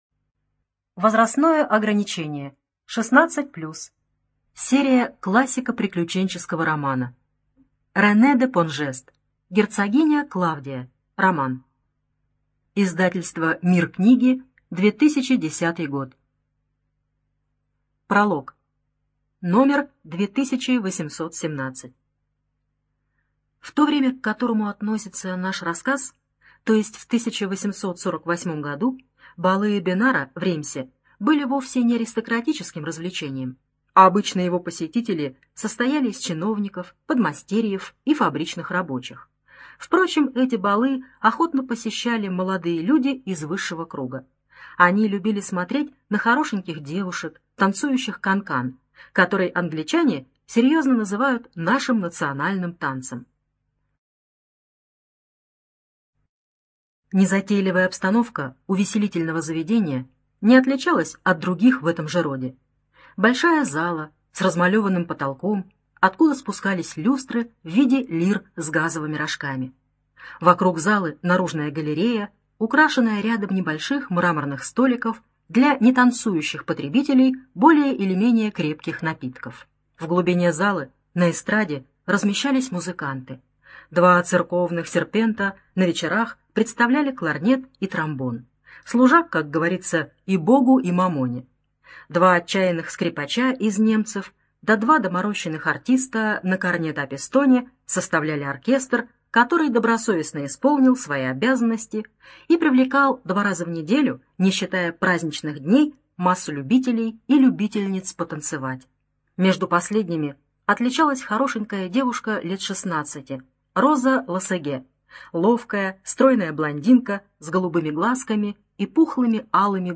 ЖанрПриключения
Студия звукозаписиКемеровская областная специальная библиотека для незрячих и слабовидящих